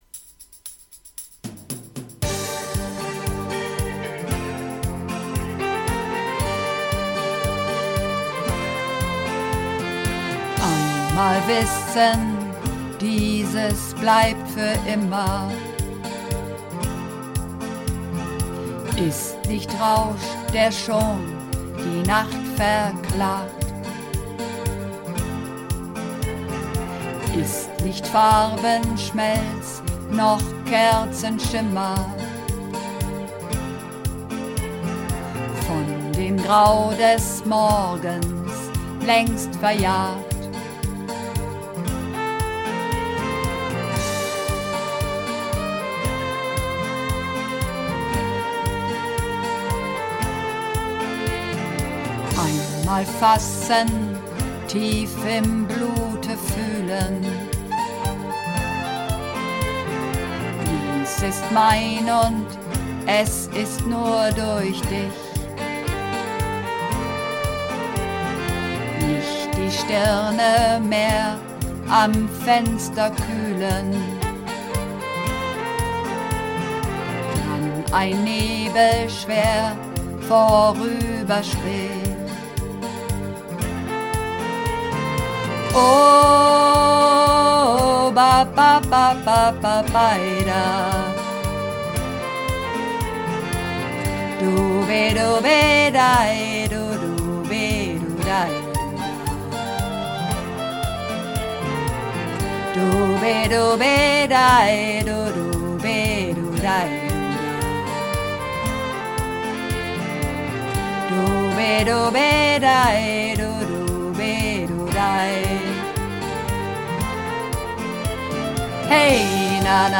Übungsaufnahmen - Am Fenster
Am Fenster (Bass - Frauen)
Am_Fenster__2_Bass_Frauen.mp3